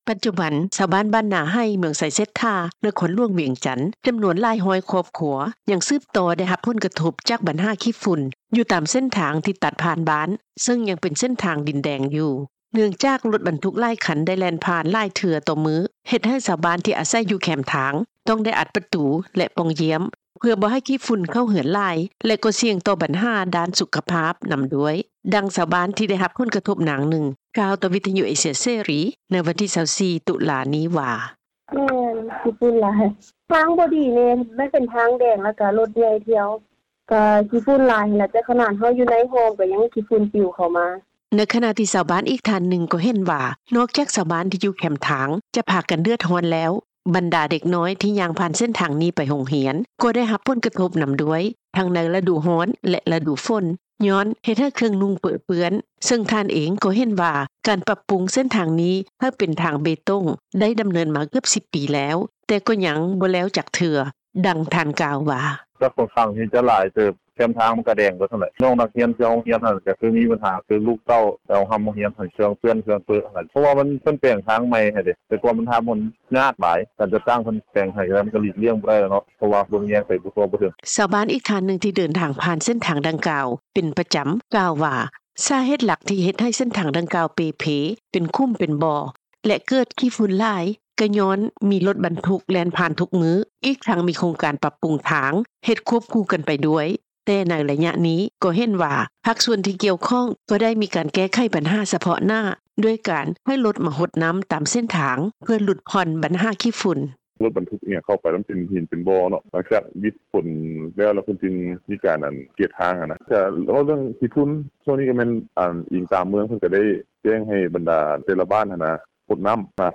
ປັດຈຸບັນ ຊາວບ້ານ ບ້ານນາໄຫ ເມືອງໄຊເສດຖາ ນະຄອນຫຼວງວຽງຈັນ ຈຳນວນຫຼາຍຮ້ອຍຄອບຄົວ ໄດ້ຮັບຜົນກະທົບຈາກບັນຫາຂີ້ຝຸ່ນ ຢູ່ຕາມເສັ້ນທາງທີ່ຕັດຜ່ານບ້ານ ເຊິ່ງເປັນເສັ້ນທາງດິນແດງ ເນື່ອງຈາກລົດບັນທຸກຫຼາຍຄັນ ໄດ້ແລ່ນຜ່ານຫຼາຍເທື່ອຕໍ່ມື້ ເຮັດໃຫ້ຊາວບ້ານທີ່ອາໄສຢູ່ແຄມທາງ ຕ້ອງໄດ້ອັດປະຕູ ແລະປ່ອງຢ້ຽມ ເພື່ອບໍ່ໃຫ້ຂີ້ຝຸ່ນເຂົ້າເຮືອນຫຼາຍ ແລະກໍ່ສ່ຽງຕໍ່ບັນຫາດ້ານສຸຂະພາບນຳດ້ວຍ, ດັ່ງ ຊາວບ້ານ ທີ່ໄດ້ຮັບຜົນກະທົບ ນາງໜຶ່ງ ກ່າວຕໍ່ວິທຍຸເອເຊັຽເສຣີ ໃນວັນທີ 24 ຕຸລາ ນີ້ວ່າ:
ທາງດ້ານເຈົ້າໜ້າທີ່ ເມືອງໄຊເສດຖາ ທ່ານໜຶ່ງ ກໍ່ຍອມຮັບວ່າ ບັນຫາດັ່ງກ່າວໄດ້ເກີດຂຶ້ນມາຕະຫຼອດເລື້ອຍໆ ແລະຍັງແກ້ໄຂບໍ່ຈົບບໍ່ສິ້ນ ຍ້ອນໂຄງການປັບປຸງເສັ້ນທາງດັ່ງກ່າວ ມີຄວາມຫຼ້າຊ້າມາ ຫຼາຍປີແລ້ວ ແຕ່ກໍ່ຍັງເຮັດບໍ່ແລ້ວຈັກເທື່ອ ແຕ່ກໍ່ຈະມີການ ສະເໜີໃຫ້ທາງຂັ້ນເທິງ ພິຈາລະນາ ແລະ ແກ້ໄຂຕາມຂັ້ນຕອນ, ດັ່ງທ່ານກ່າວວ່າ: